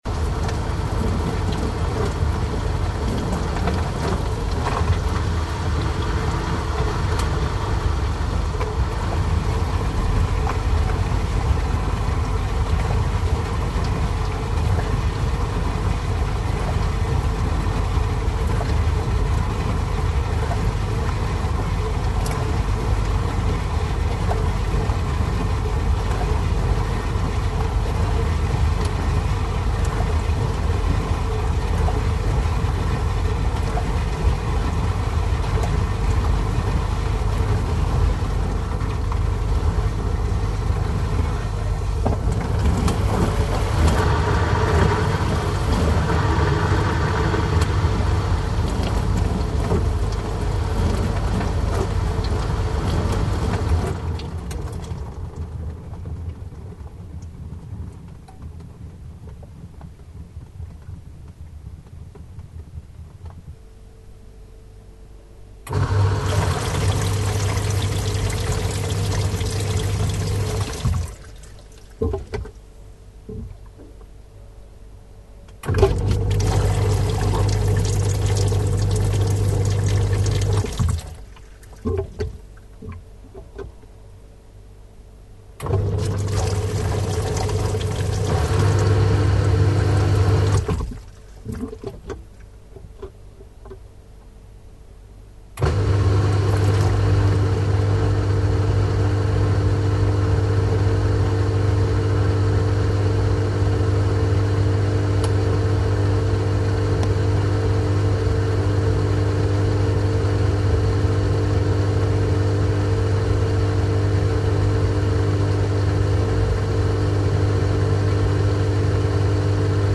Звуки посудомоечной машины
Полноценный звук работающей посудомойки